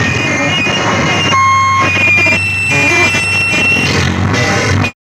5007L FEDBAK.wav